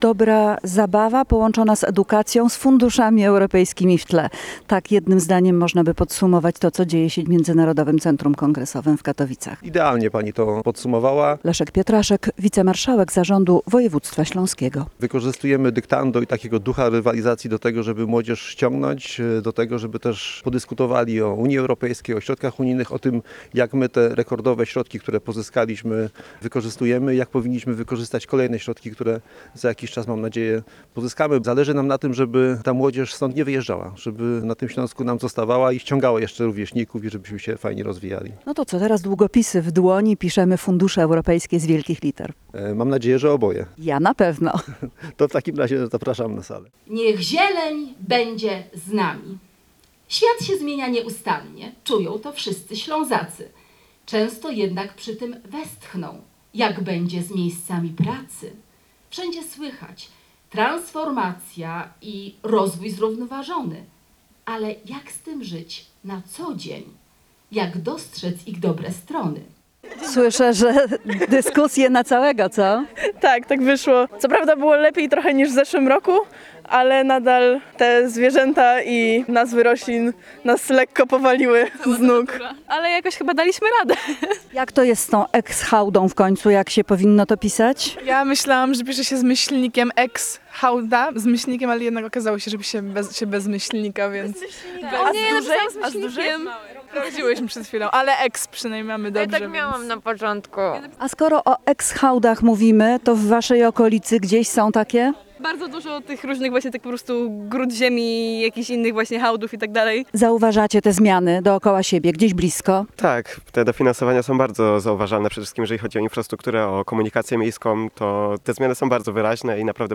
Reportaż z Konkursu Ortograficznego "Eurodyktando" 2025